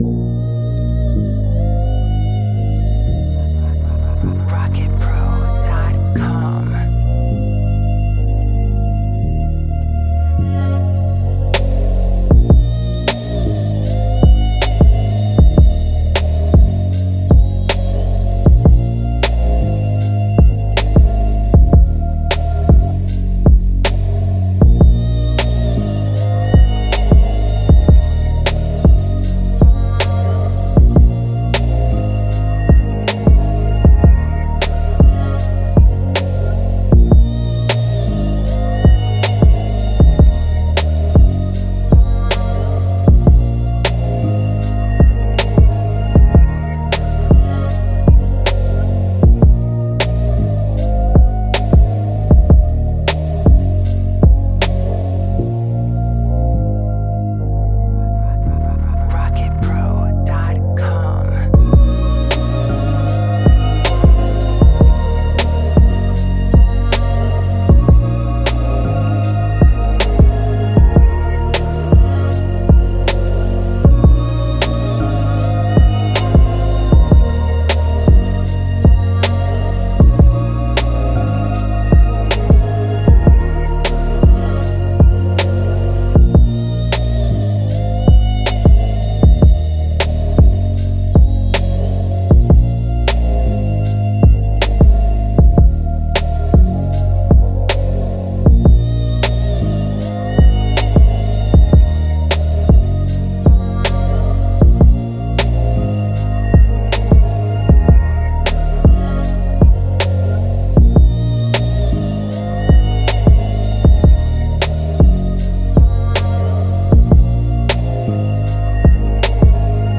East Coast